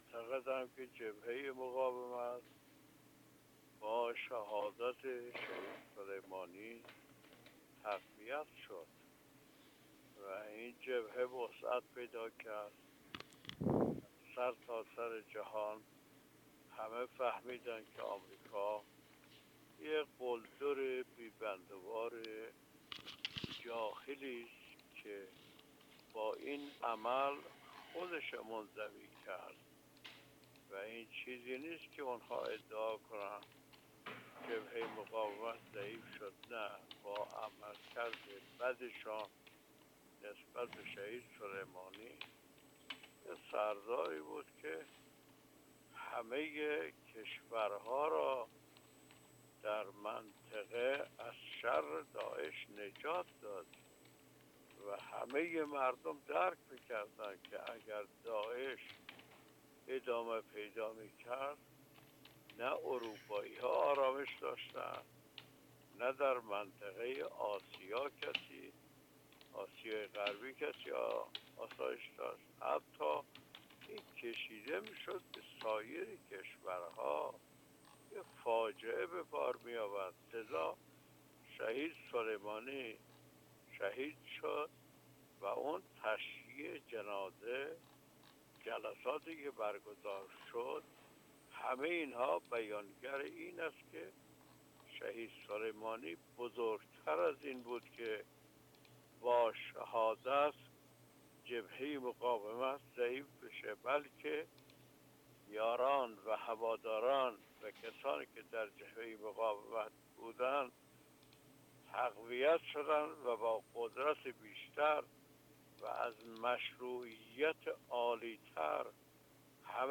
در گفت‌وگو با خبرنگار ایکنا